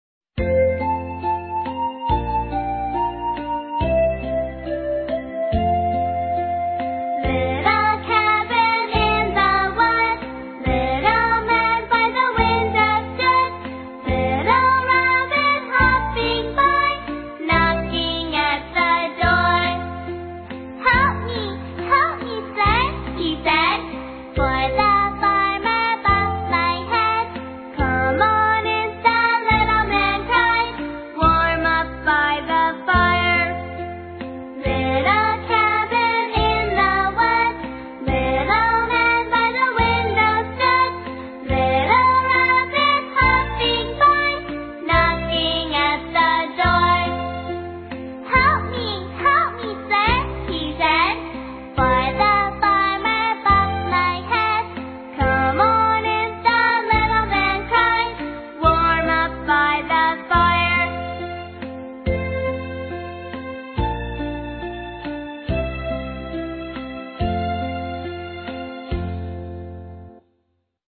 在线英语听力室英语儿歌274首 第121期:Little Cabin in the Wood的听力文件下载,收录了274首发音地道纯正，音乐节奏活泼动人的英文儿歌，从小培养对英语的爱好，为以后萌娃学习更多的英语知识，打下坚实的基础。